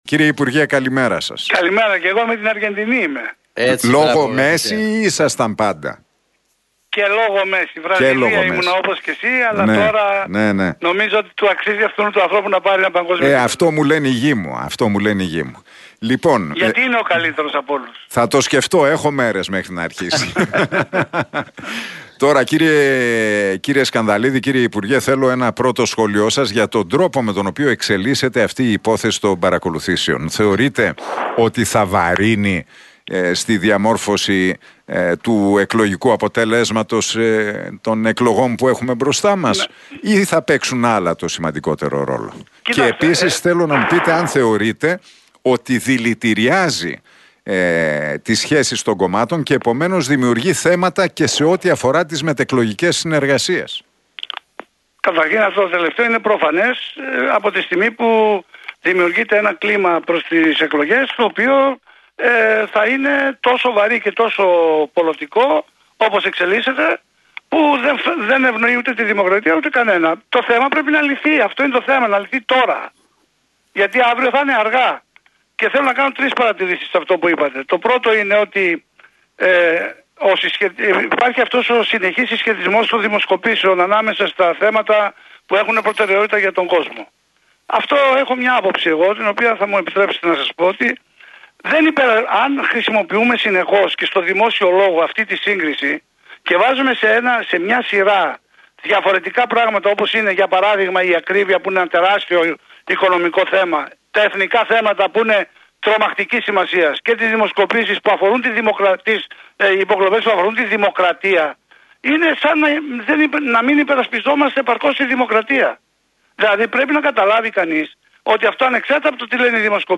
σε συνέντευξή του στον Realfm 97,8